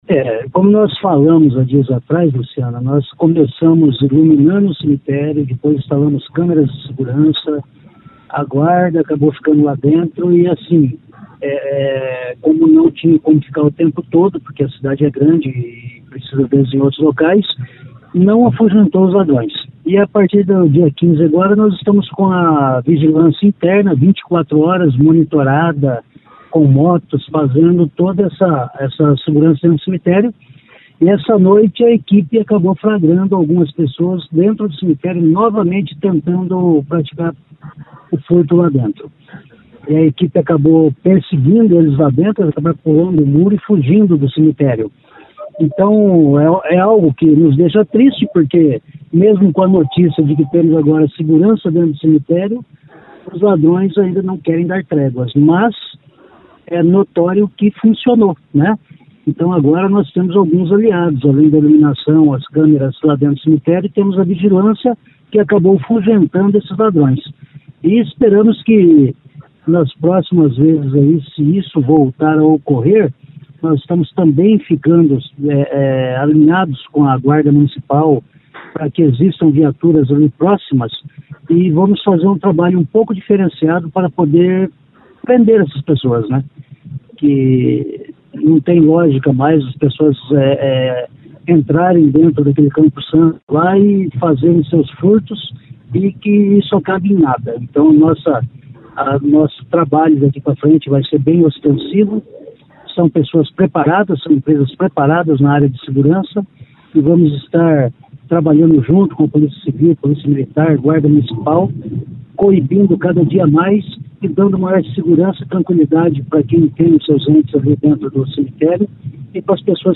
Os suspeitos pularam o muro e fugiram, mas não levaram as peças. Ouça o que diz o secretário de Infraestrutura, Vagner Mussio.